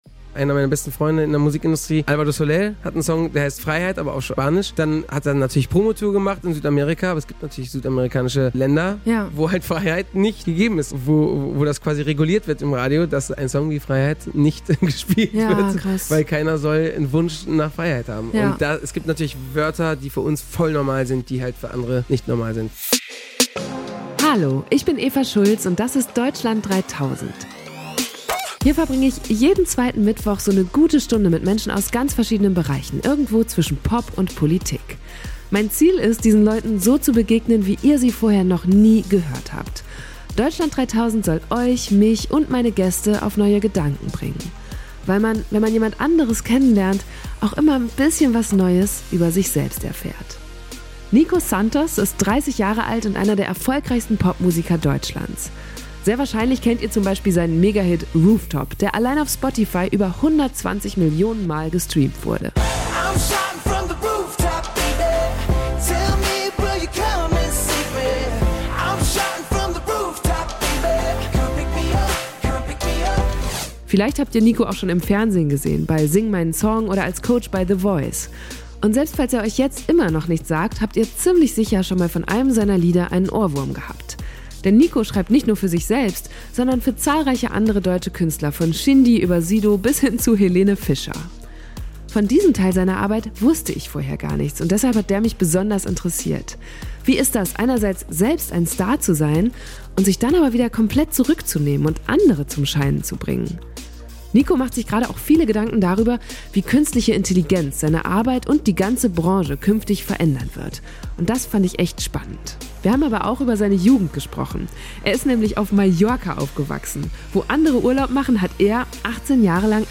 Damit hatten wir allerdings beide nicht gerechnet und so saßen wir uns in viel zu warmen Winter backstage im Deutschland3000 Studio gegenüber. Das war ein sehr angenehmes, lustiges und offenes Gespräch und am Ende kam raus, dass hinter Nicos sonnigem Gemüt noch viel mehr steht als nur sein Aufwachsen im Süden.